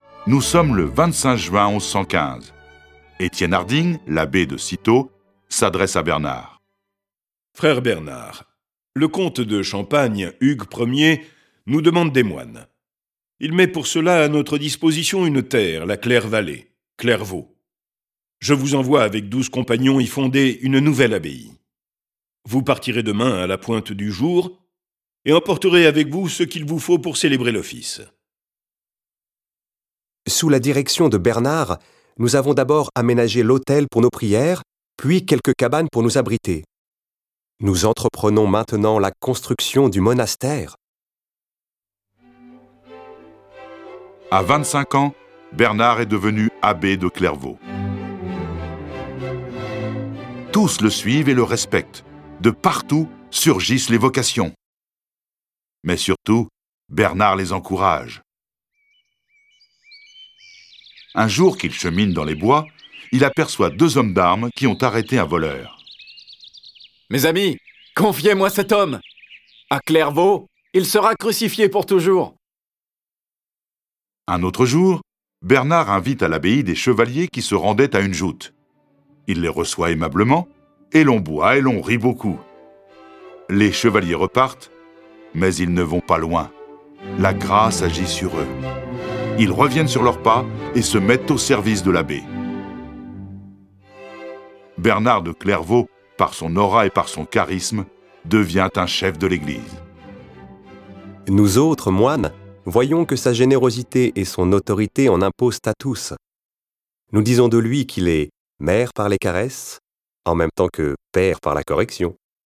De là, il voyage et est reçu par les plus grandes cours d'Europe. Découvrez la vie de l'un des plus importants hommes d'église qui sera à l'origine des Templiers. Cette version sonore de la vie de Bernard est animée par dix voix et accompagnée de près de quarante morceaux de musique classique.